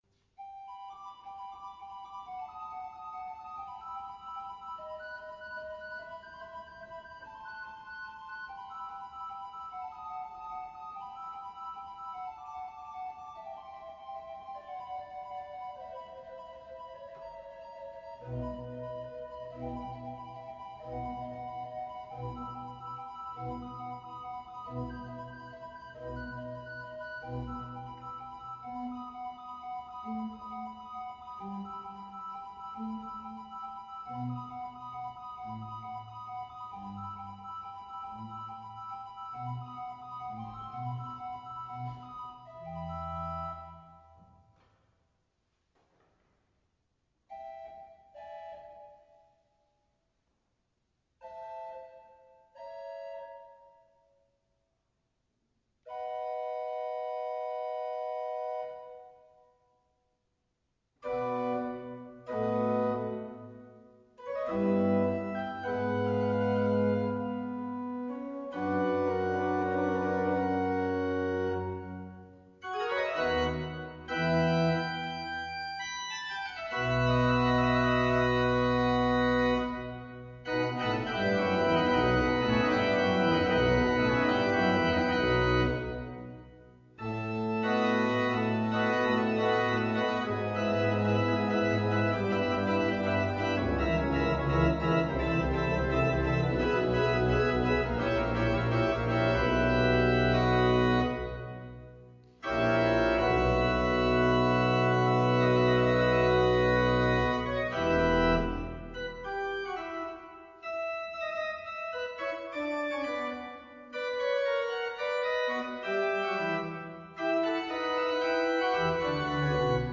Central-Church-4-19-20-radio-worship-CD.mp3